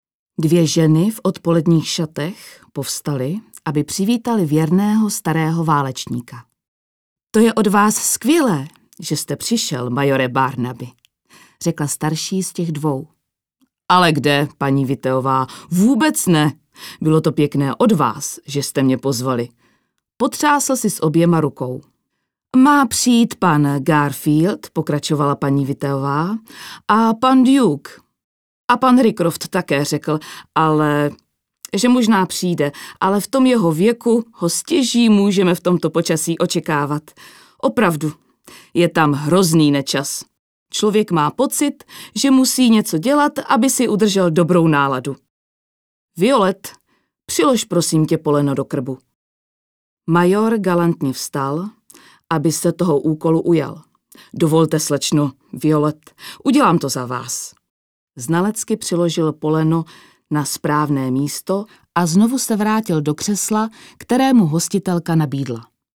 Audioknihy:
Herečka